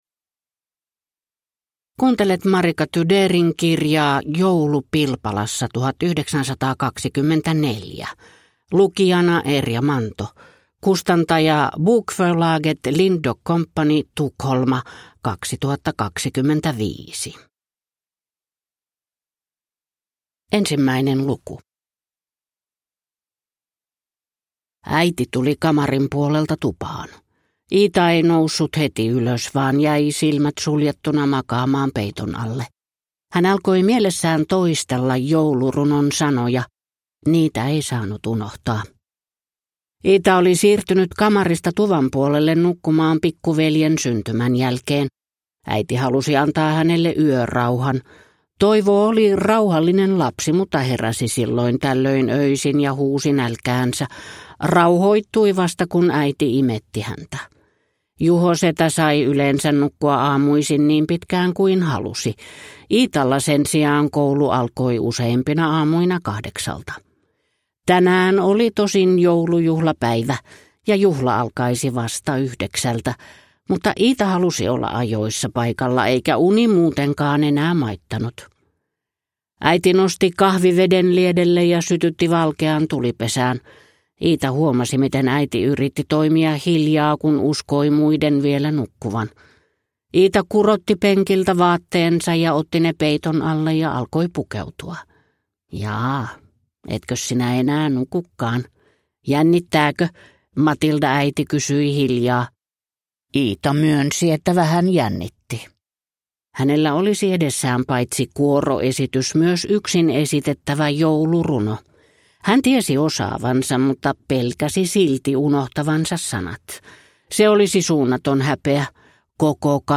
Joulu Pilpalassa 1924 (ljudbok) av Marika Tudeer